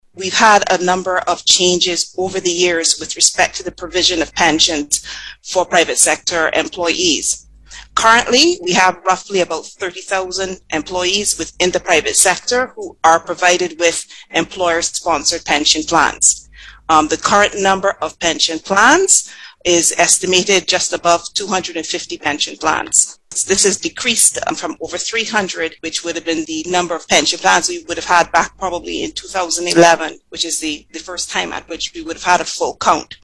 She made this disclosure while speaking during a panel discussion on VOB’s Down To Brasstacks on Sunday.